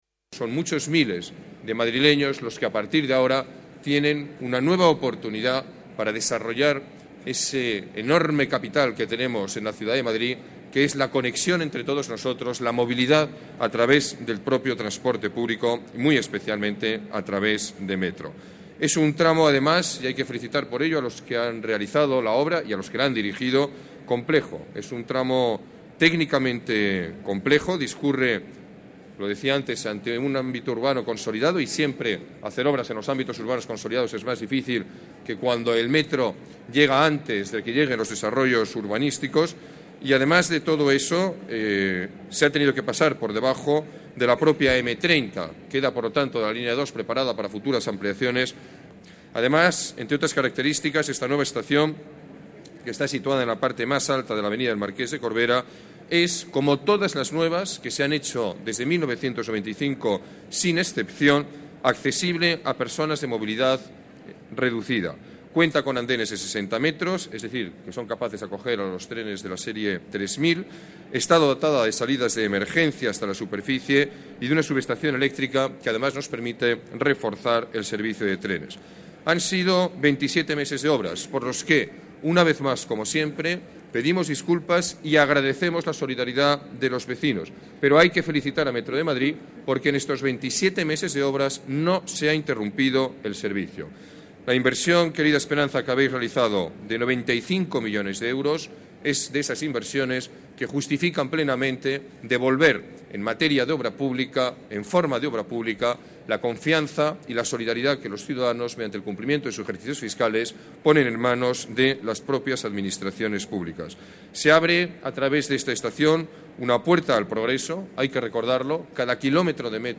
Nueva ventana:Declaraciones del alcalde de la Ciudad de Madrid, Alberto Ruiz-Gallardón